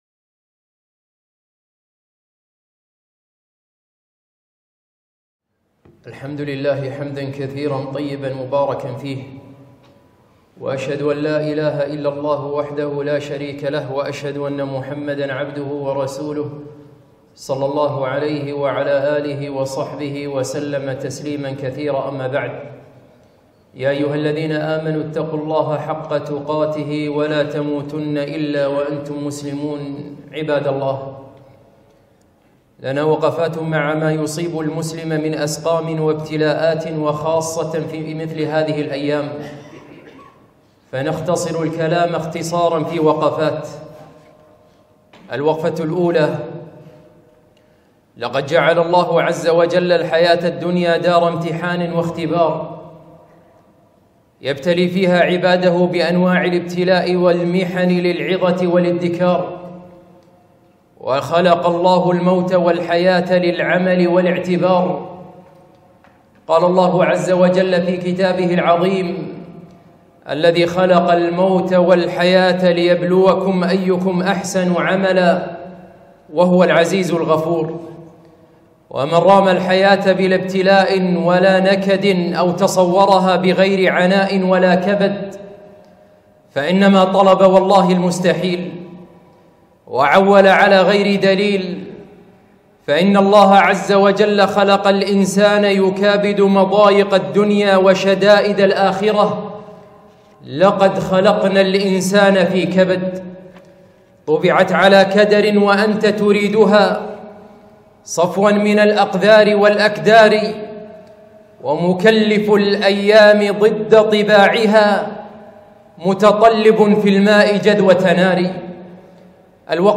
خطبة - موقفنا من الأسقام